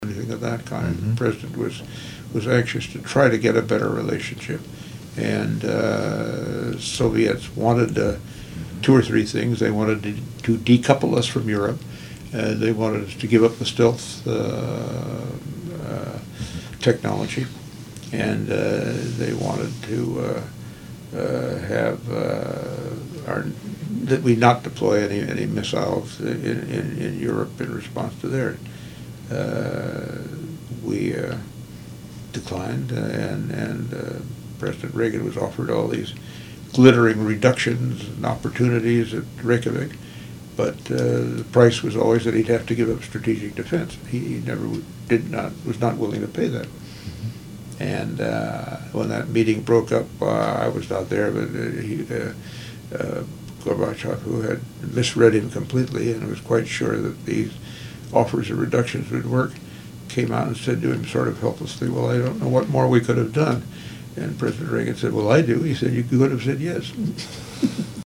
Commenting on what Gorbachev might have done to have saved the agreement, Secretary of Defense Caspar Weinberger relayed Reagan's cheeky answer. Date: November 19, 2002 Participants Caspar Weinberger Associated Resources Caspar Weinberger Oral History The Ronald Reagan Presidential Oral History Audio File Transcript